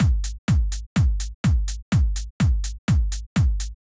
Drumloop 125bpm 04-C.wav